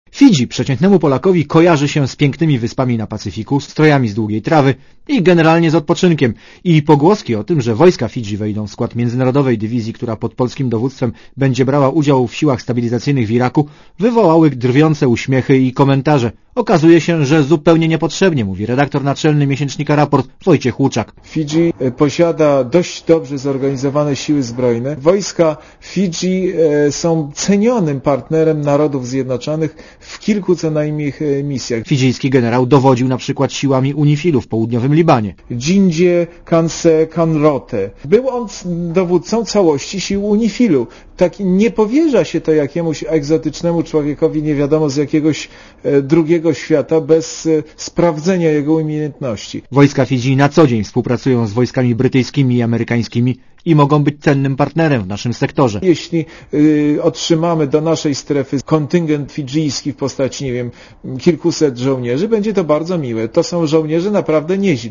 O tym reporter Radia Zet.